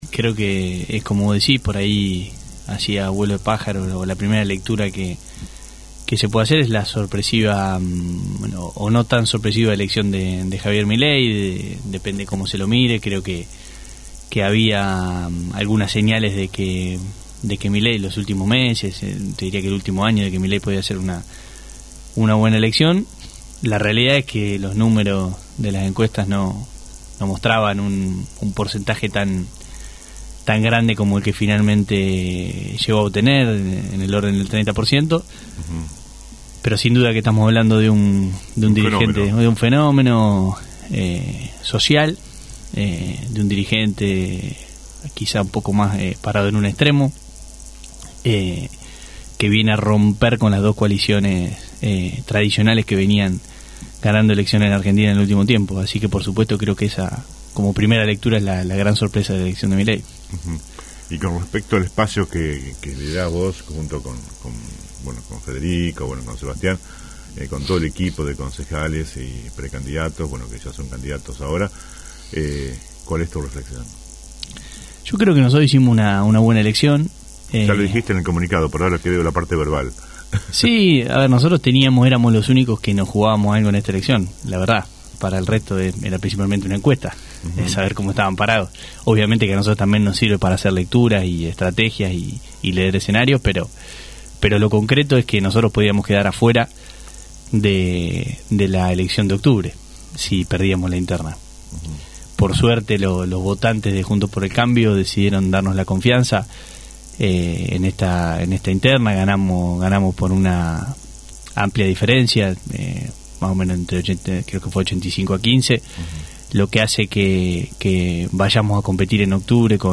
Las urnas en cada elección hablan, y esta semana estaremos conversando con distintos referentes de los tres partidos que se presentaron en Las Flores con pre candidatos locales. En el día de la fecha, dialogamos con el concejal de Juntos por el Cambio Valentín Gennuso.